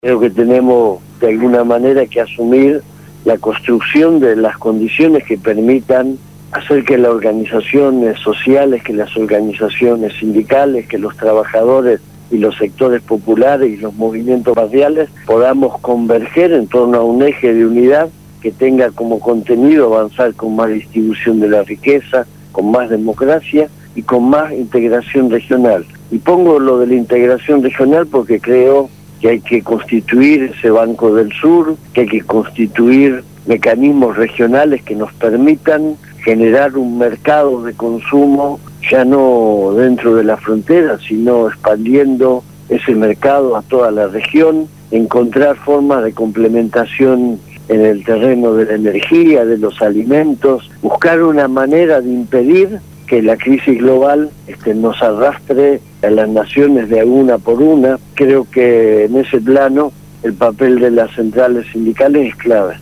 Entrevista a Hugo Yasky, Secretario General CTA de los Trabajadores